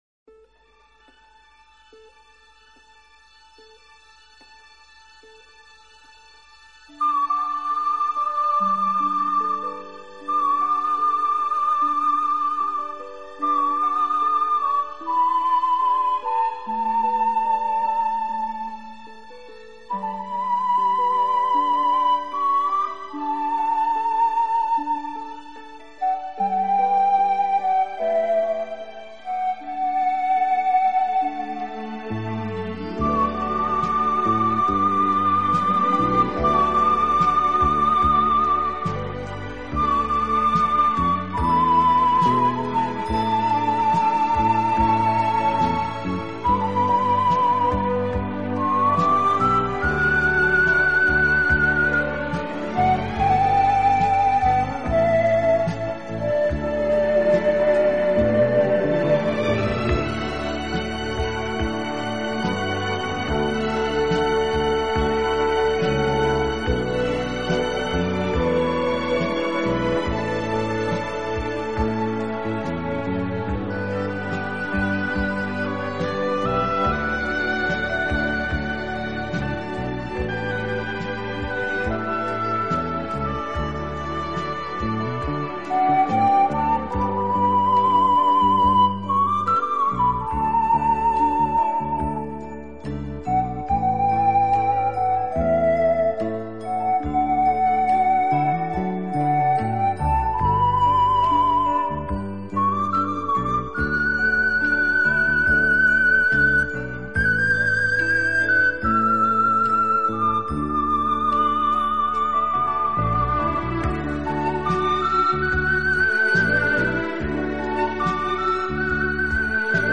超广角音场的空间感演绎，大自然一尘不染的精华，仿佛让你远离凡尘嚣暄，